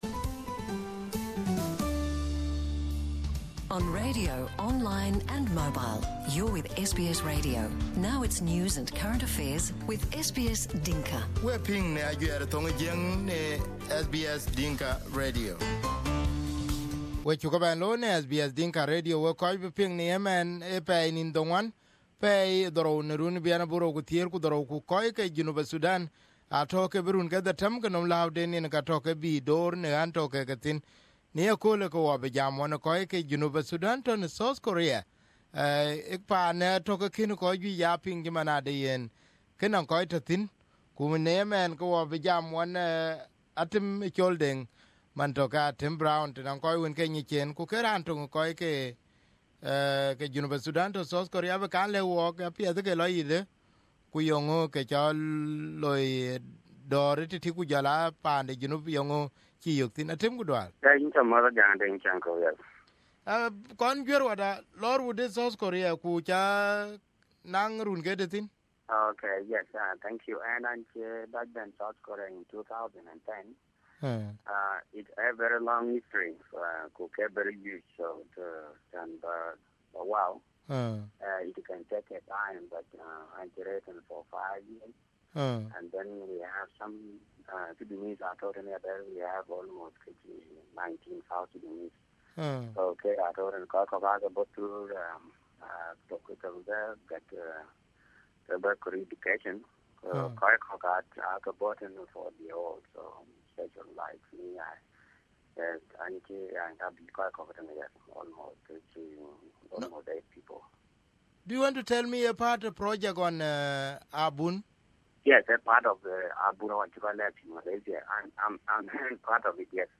SBS Dinka